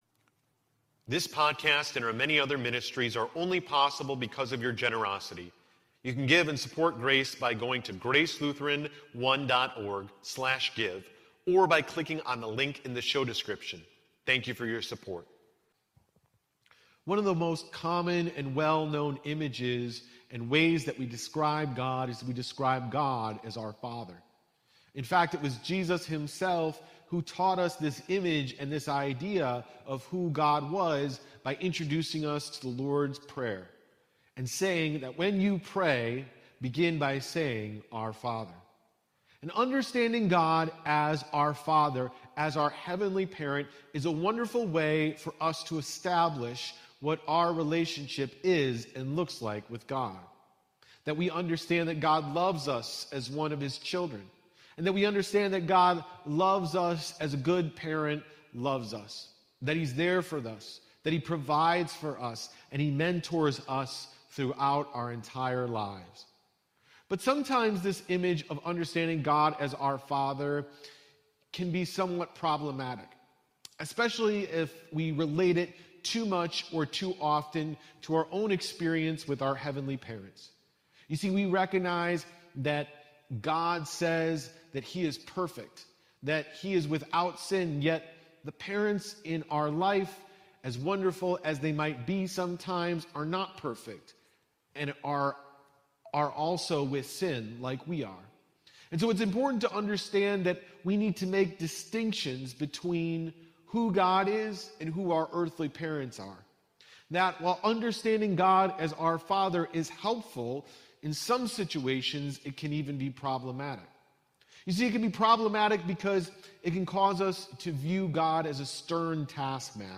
Sermon (4.24.22)